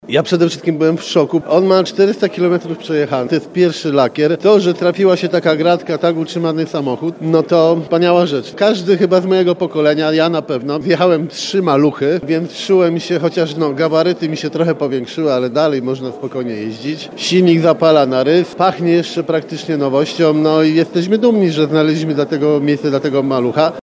Auto testował prezydent Bielska-Białej, Jarosław Klimaszewski. Tak mówi o wrażeniach: